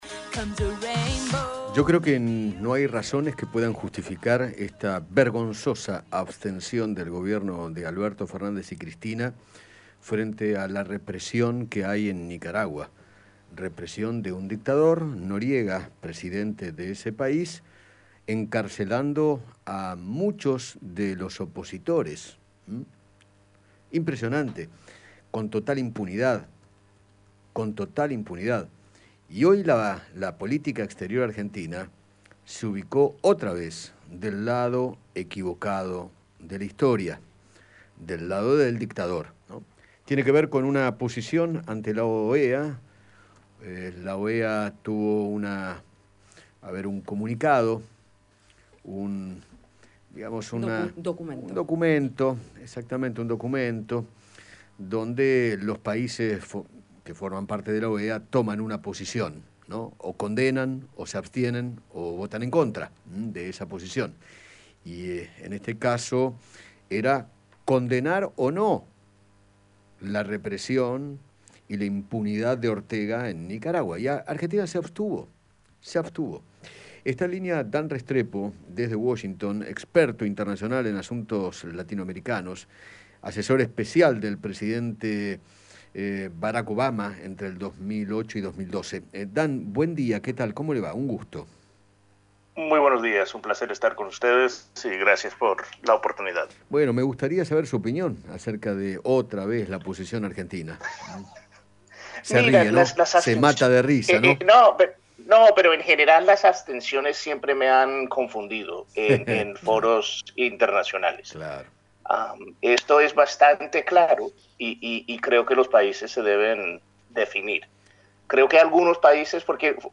Dan Restrepo, ex asistente personal de Barack Obama, dialogó con Eduardo Feinmann sobre la abstención de la Argentina ante las violaciones de derechos humanos en Nicaragua.